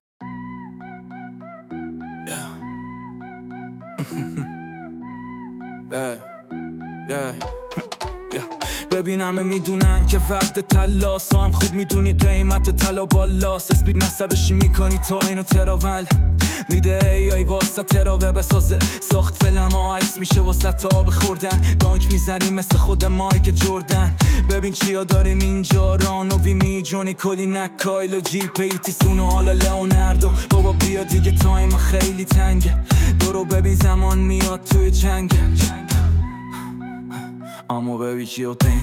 نمونه کار تغییر صدا و موزیک